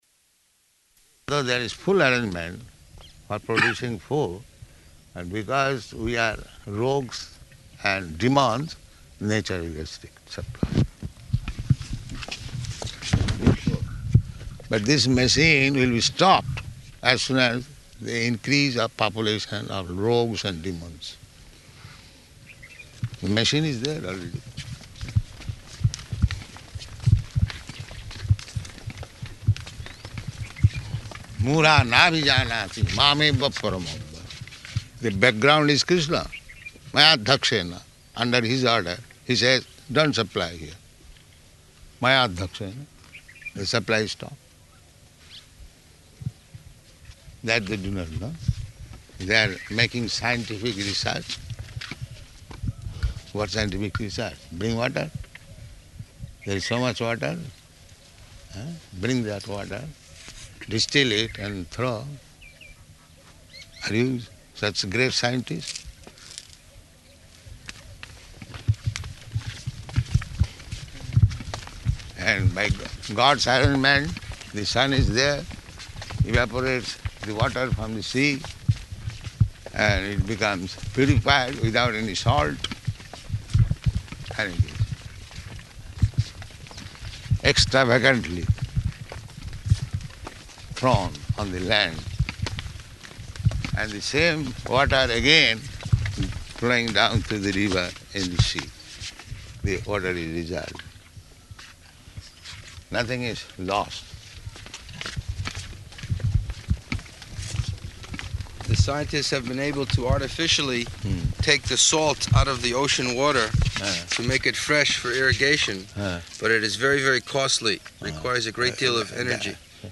Type: Walk
Location: Vṛndāvana